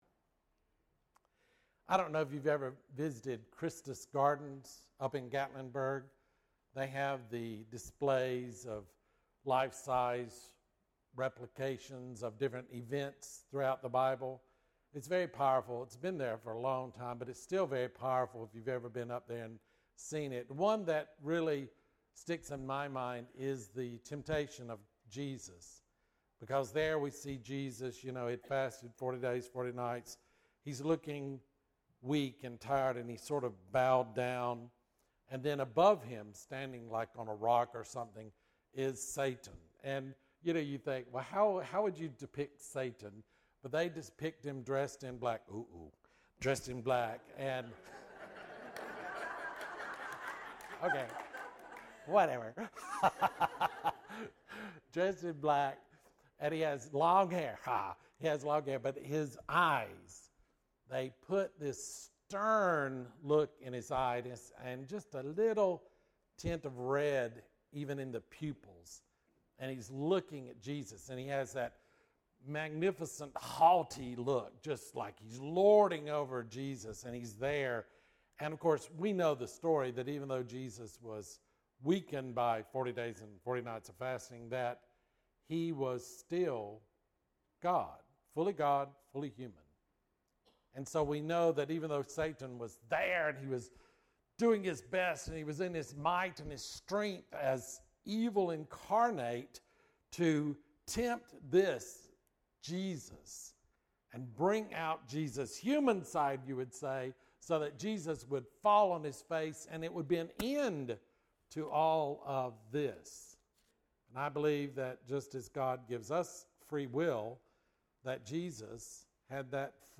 Bible Text: Matthew 4:1-11 | Preacher